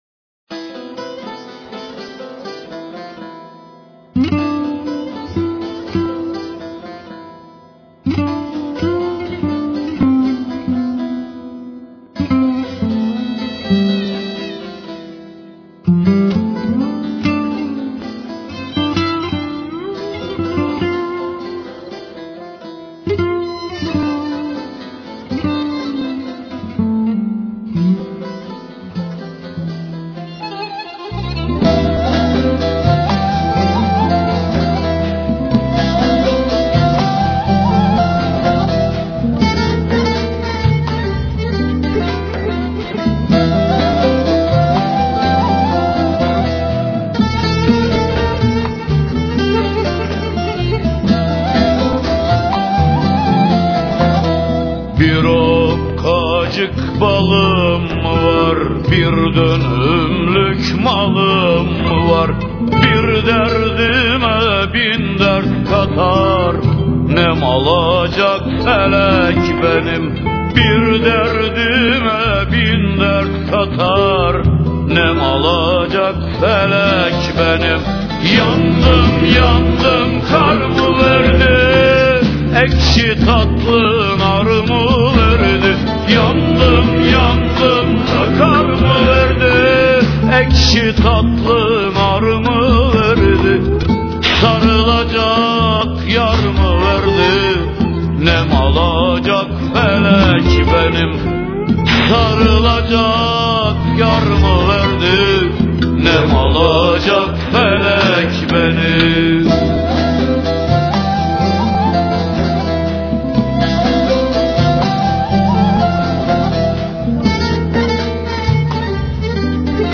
Anadolu' dan Türküler